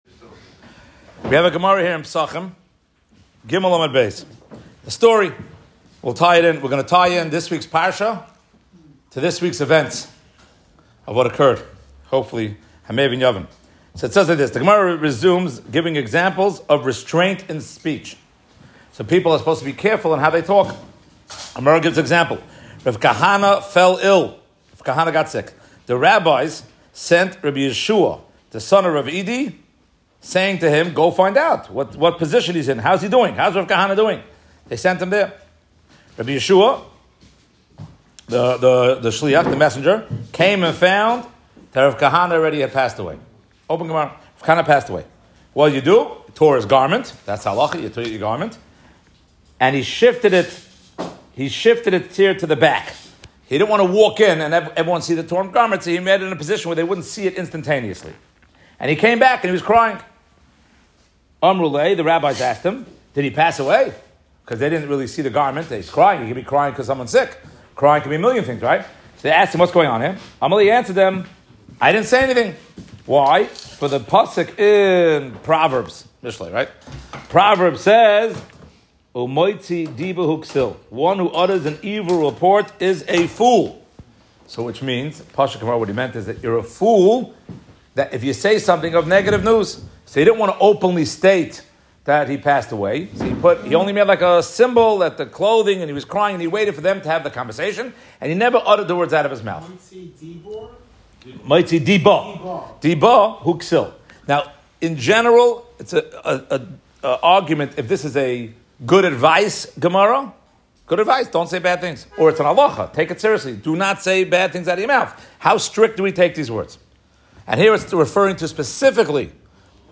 Live from Young Israel Beth El Listen Watch